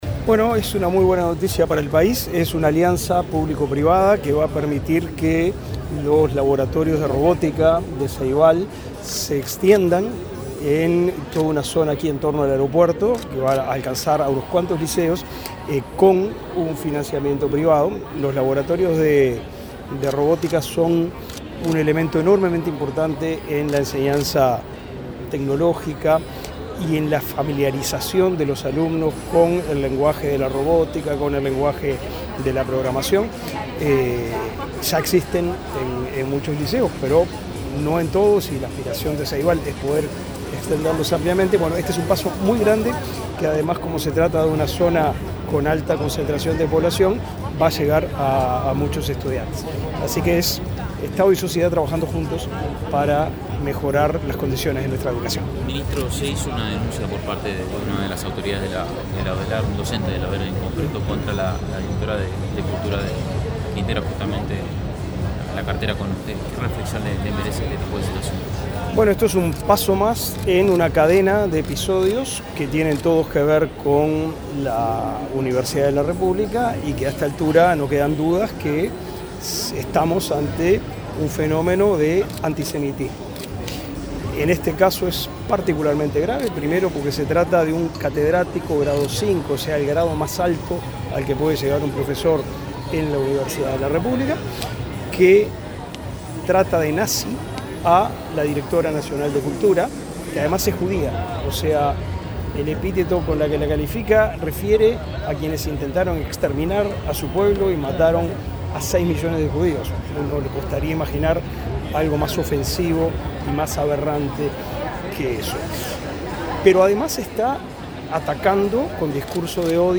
Declaraciones del titular del MEC, Pablo da Silveira
Declaraciones del titular del MEC, Pablo da Silveira 28/05/2024 Compartir Facebook X Copiar enlace WhatsApp LinkedIn Este 28 de mayo, Ceibal y la Corporación América Airports, empresa que gestiona Aeropuertos Uruguay, firmaron un acuerdo para el desarrollo de una nueva etapa del programa Ceilab en Canelones. Antes del evento, el titular del Ministerio de Educación y Cultura (MEC), Pablo da Silveira, realizó declaraciones a la prensa.